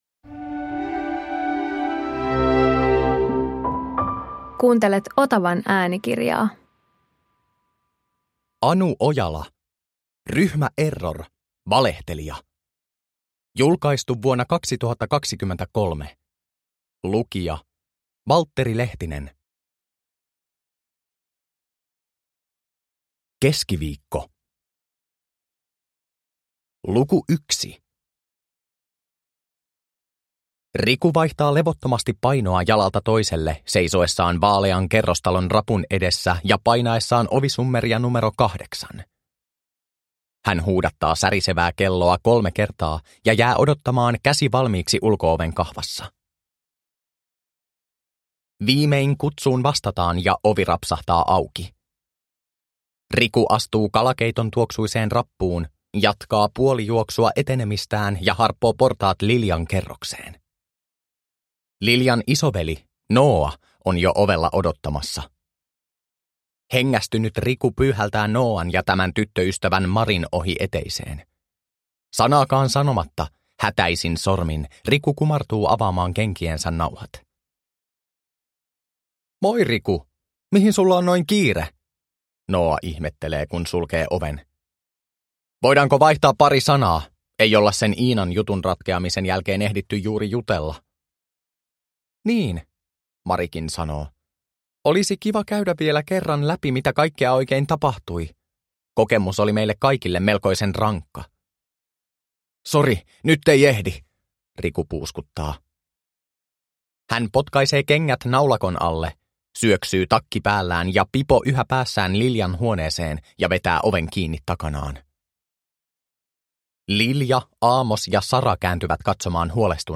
Ryhmä Error - Valehtelija – Ljudbok – Laddas ner